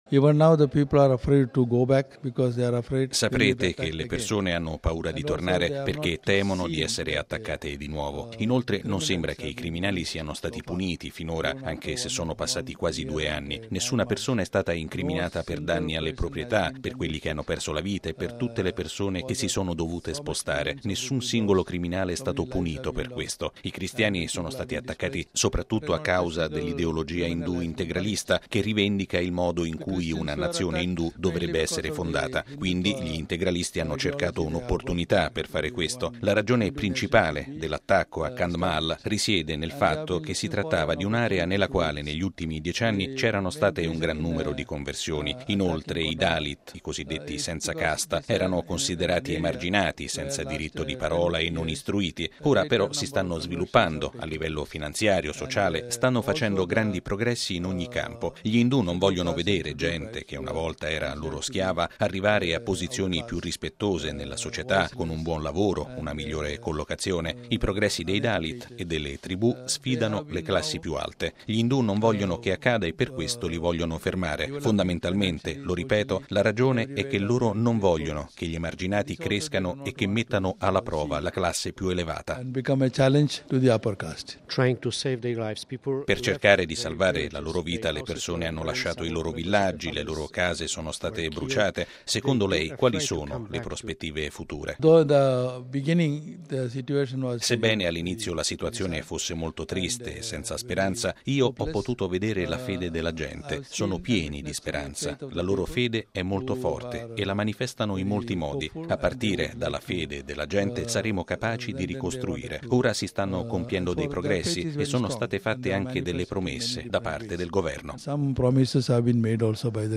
R. - You will know that people...